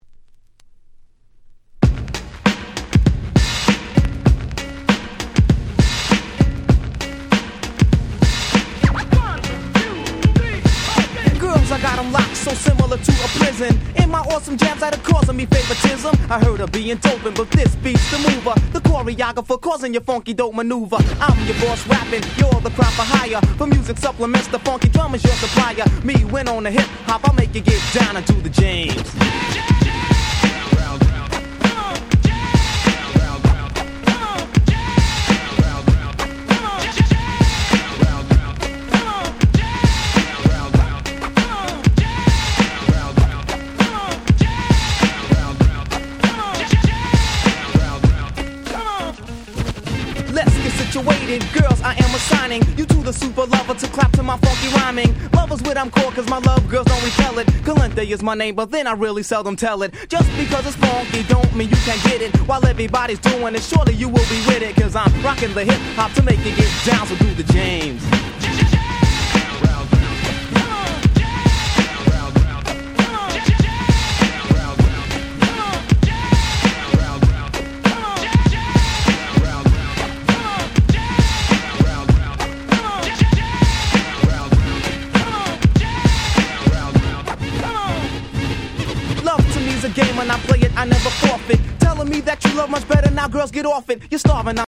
87' Hip Hop Super Classics !!!!!
激重なImpeach Beatが最強な問答無用のHip Hop Classics !!!
80's Middle School ミドルスクール Boom Bap ブーンバップ